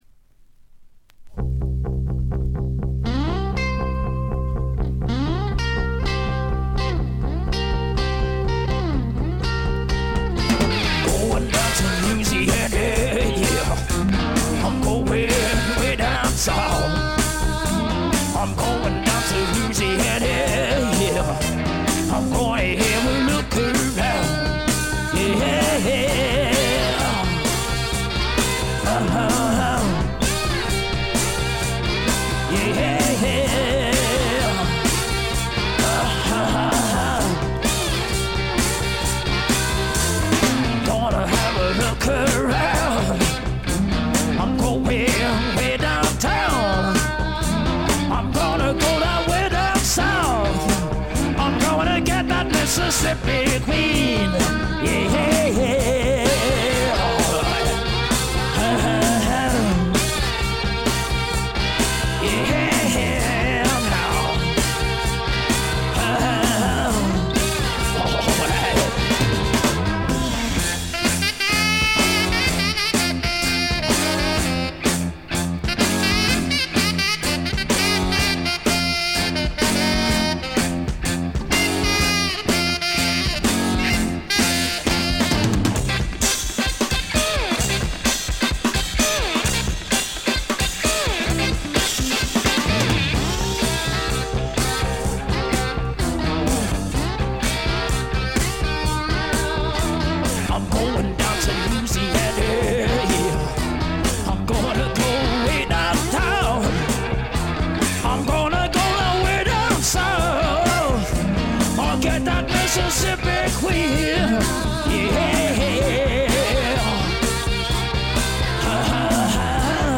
へヴィー・ブルース、ハード・スワンプな名作を残しました。
試聴曲は現品からの取り込み音源です。
lead vocals
saxophone, organ, piano
electric and acoustic guitars
steel guitar, backing vocals, mandolin, marimba
bass, backing vocals
drums, percussion
Recorded at I.B.C. Studios, London, September 1969